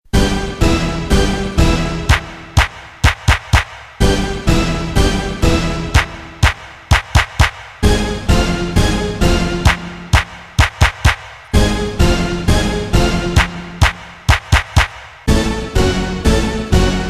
Tags: Princeton Hockey Baker Rink